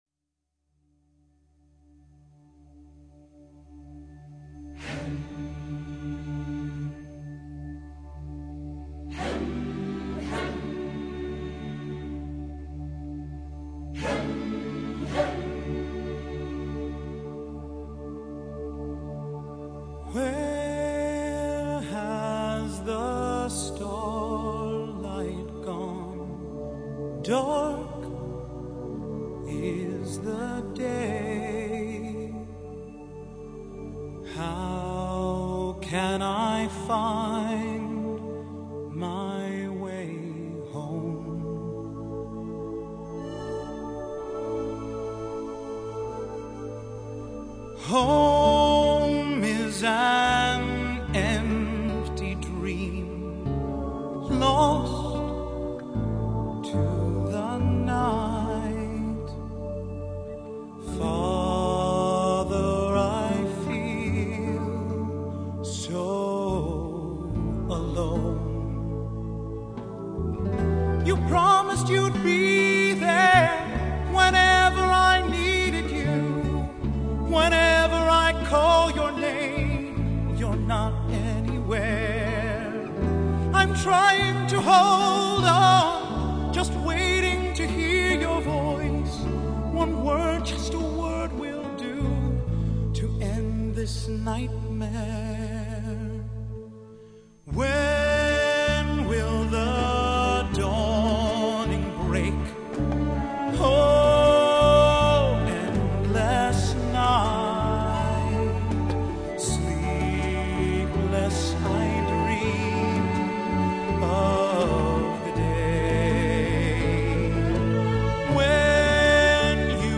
the addition of English lyrics